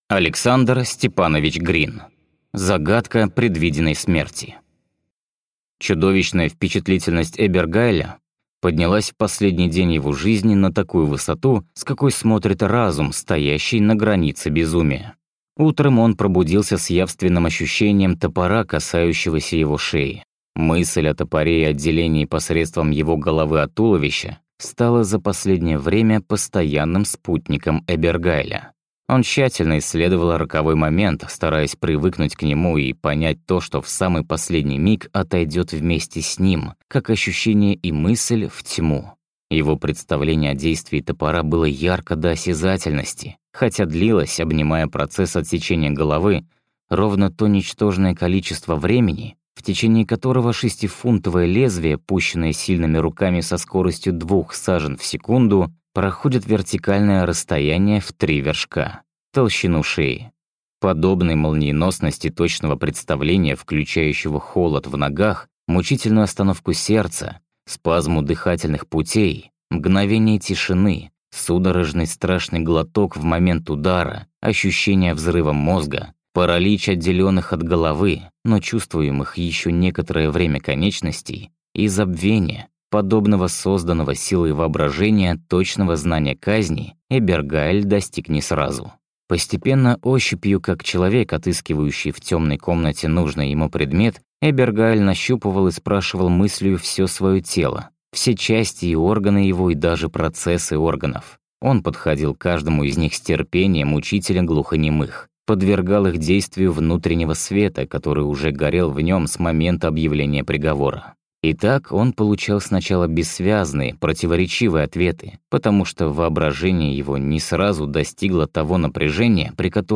Аудиокнига Загадка предвиденной смерти | Библиотека аудиокниг